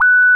sine11 f#5.wav